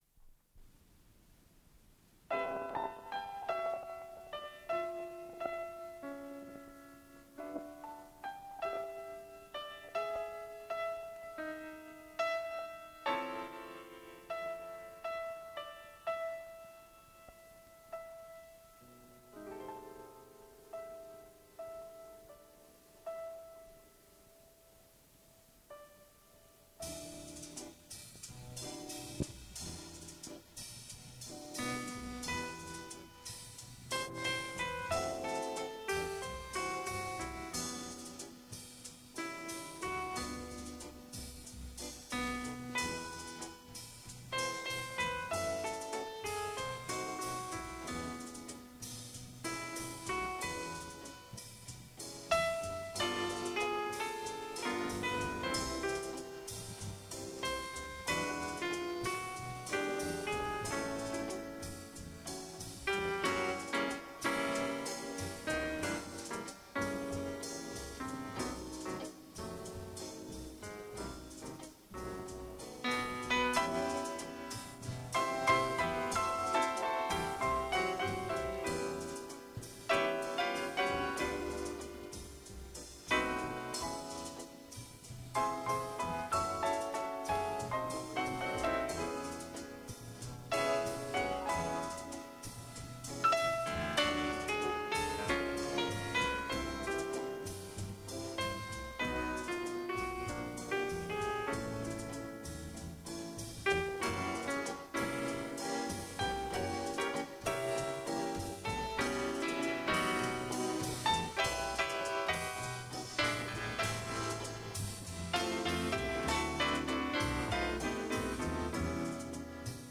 Это рулон пришедшей с ГДРЗ, Дубль оригинала.